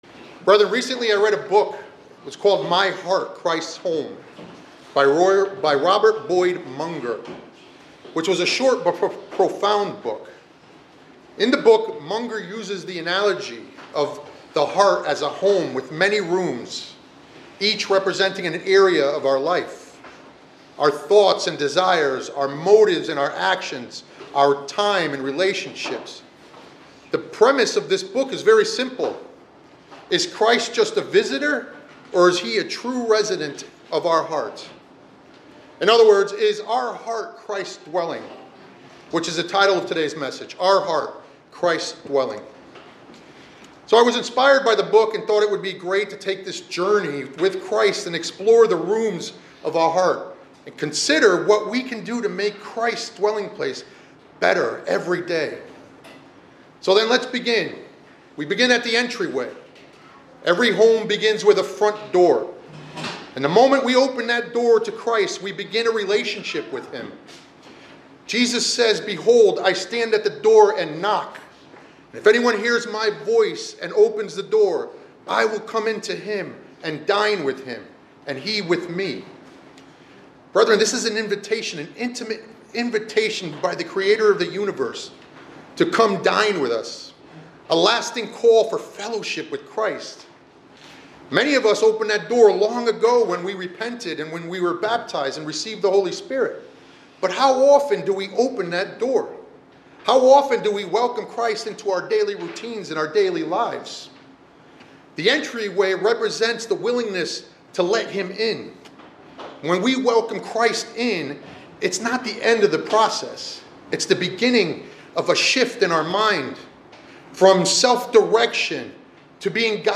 The sermonette explores the analogy of the heart as a home for Christ, inspired by Robert Boyd Munger’s book “My Heart, Christ’s Home.” It guides listeners through various “rooms” of the heart, each representing different aspects of life, and asks whether Christ is merely a guest or the true resident of our hearts.